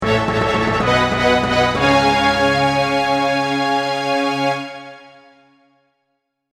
RPG系ジングル
RPG系ジングル.mp3